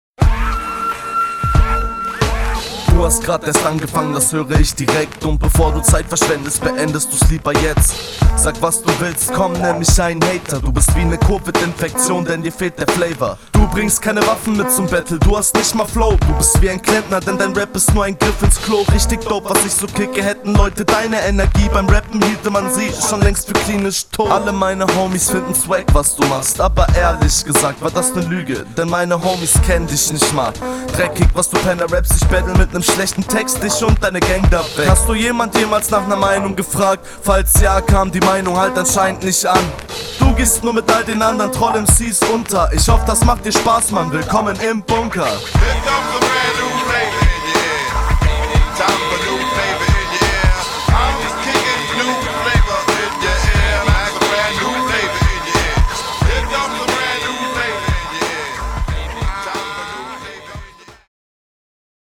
Cooler Vibe, musste sogar bisschen mitnicken.
Chilliger Beat!